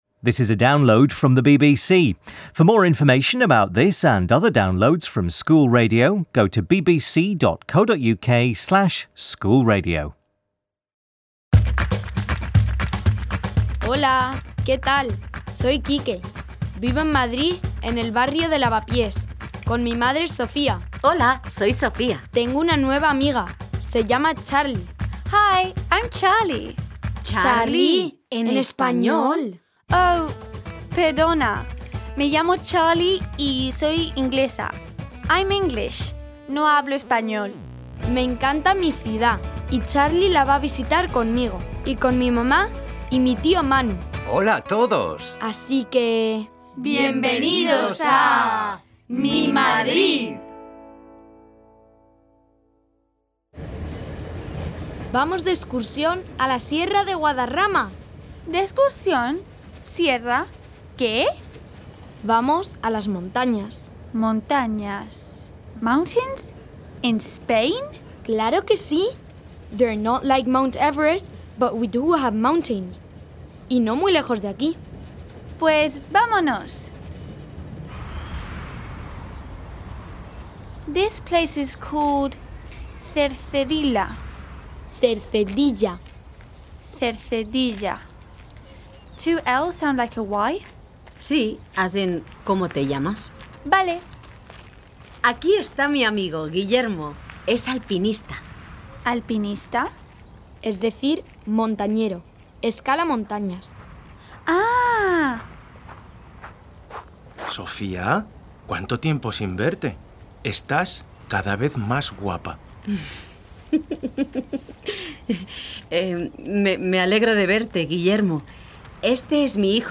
Sofía takes Charlie and Quique to the mountains just outside Madrid and they meet Sofía's friend, Guillermo, a mountaineer. Teresa tells a story about two rival mountains - 'El Teide' on Tenerife and 'Popocatépetl' in Mexico - and Uncle Manu sings about the seasons. Key vocabulary includes the weather and seasons and key grammar points include making negative sentences.